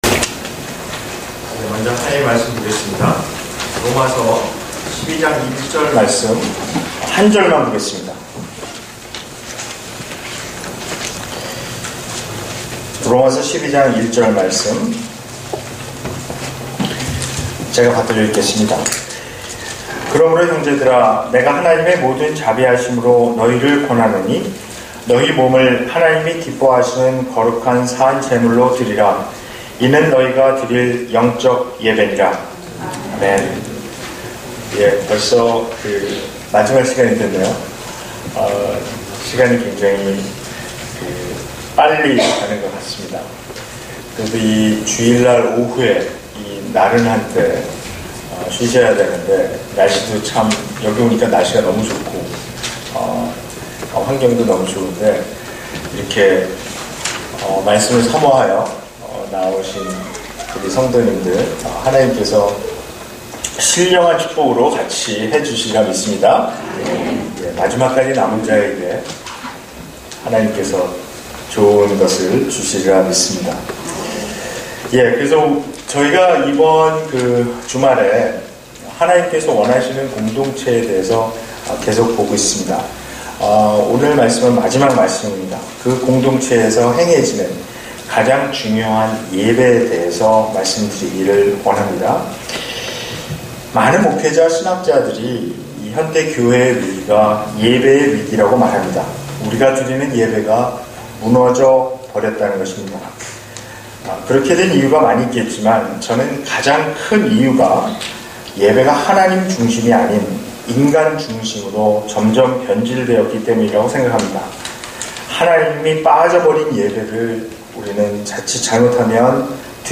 특별예배